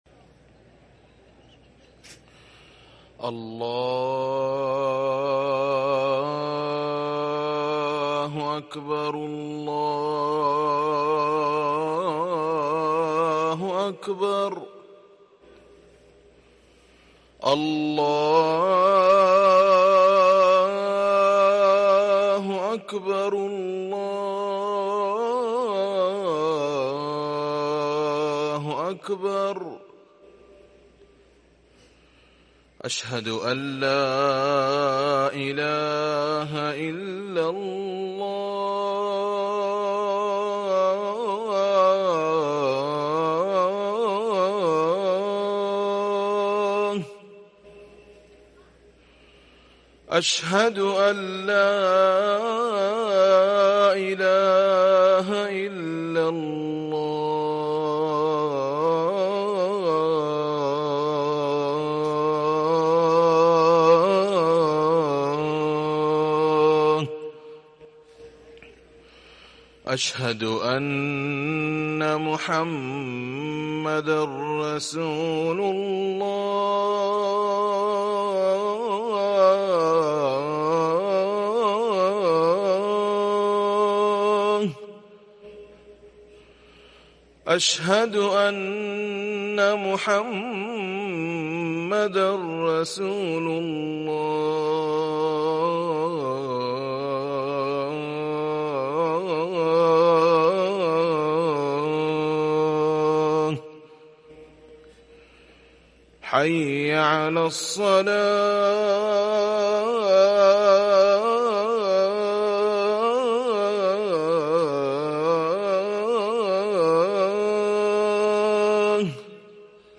أذان الفجر